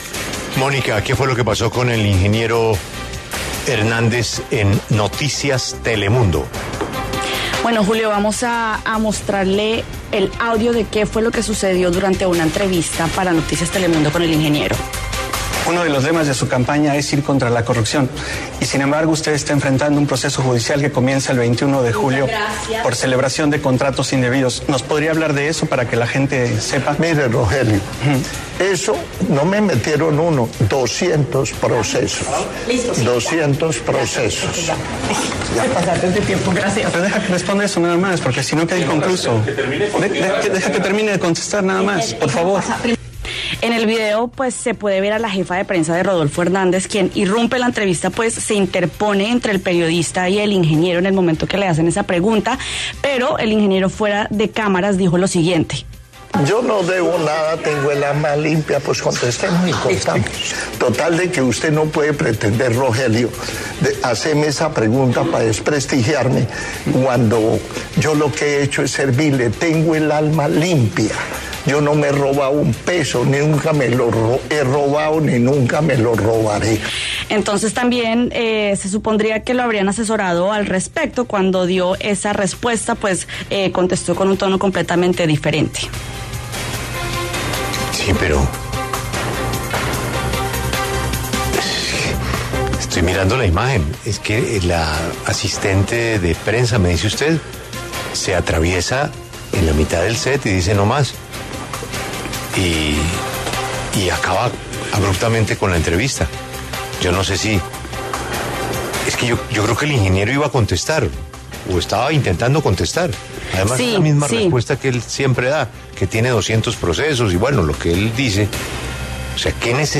Asesores de Rodolfo Hernández pararon entrevista tras pregunta sobre caso judicial
Los asesores del candidato presidencial interrumpieron una entrevista en Telemundo por “falta de tiempo”.